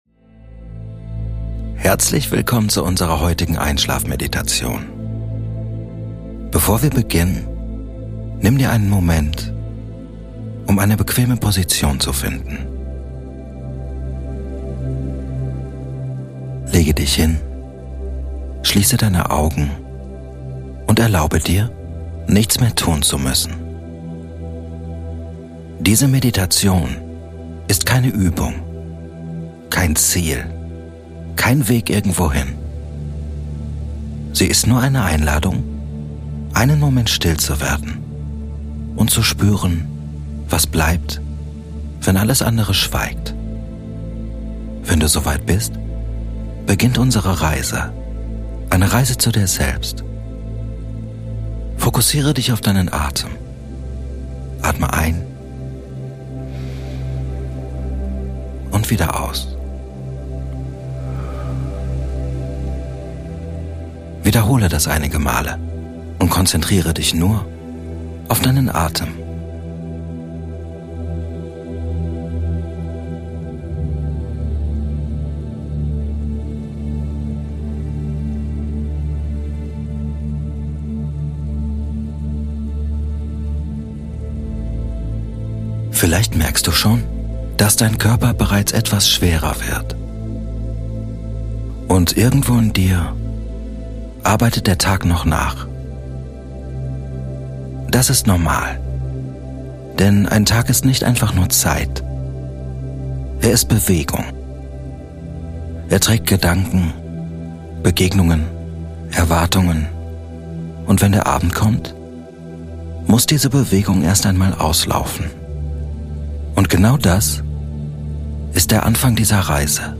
Diese Episode lädt dich ein zu einer stillen Reise zwischen Wachsein und Schlaf – eine Meditation zum Einschlafen, die dich sanft aus den Gedanken des Tages in die Ruhe der Nacht führt. Mit ruhiger Stimme und gleichmäßigem Rhythmus entsteht ein Raum, in dem du loslassen und tief entspannen kannst.